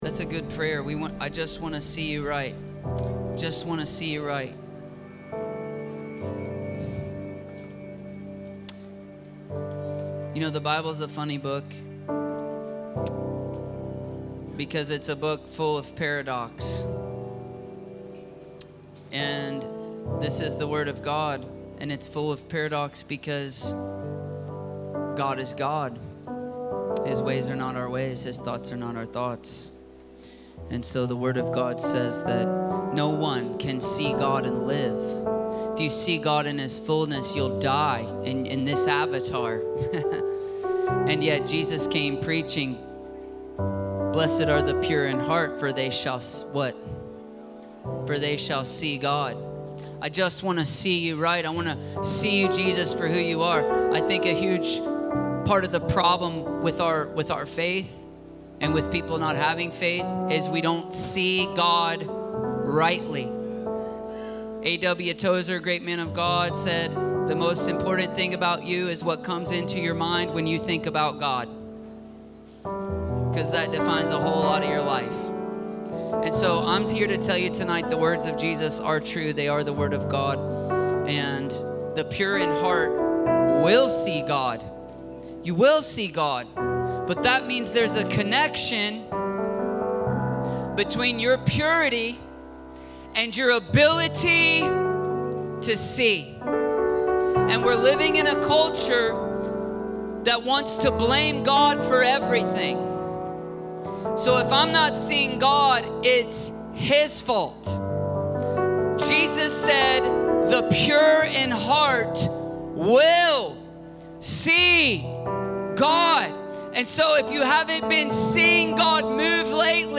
Embrace The Tension - Stand Alone Messages ~ Free People Church: AUDIO Sermons Podcast